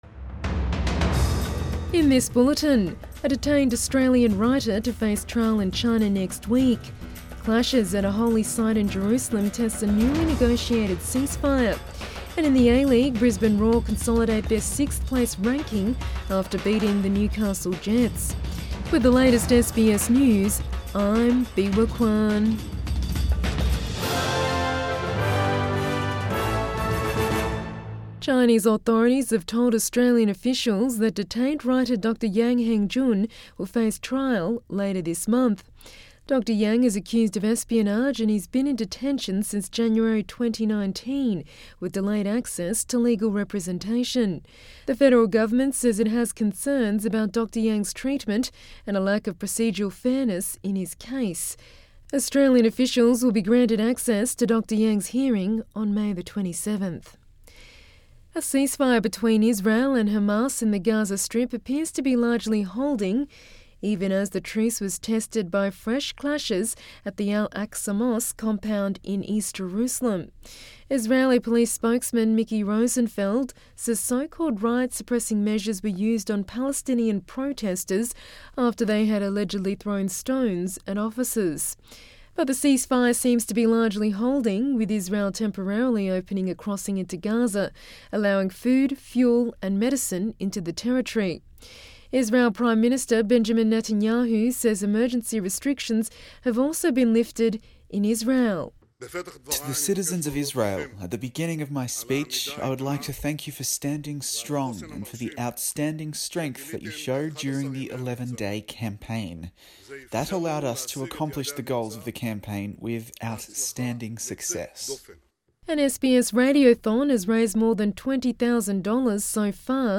AM bulletin 22 May 2021